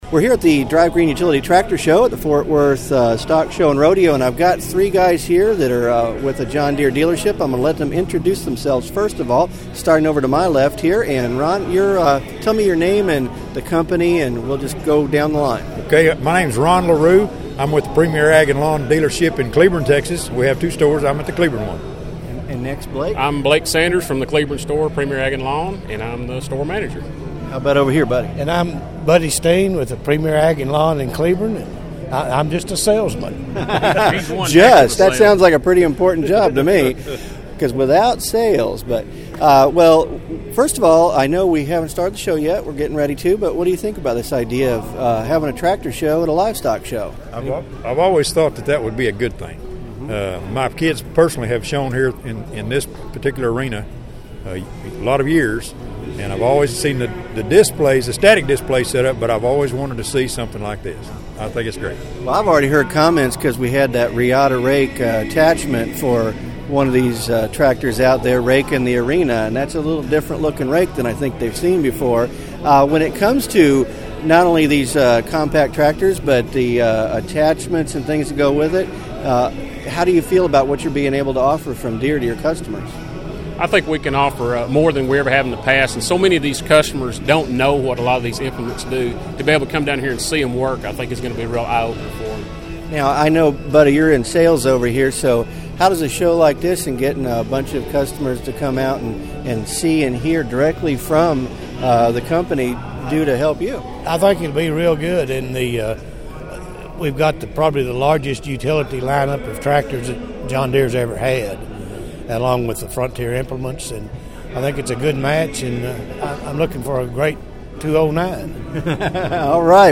Ft. Worth Area DealersWhen I was in Ft. Worth recently at the Stock Show I met a group of local dealers who were there to see the show and then the rodeo.